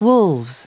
Prononciation du mot wolves en anglais (fichier audio)
Prononciation du mot : wolves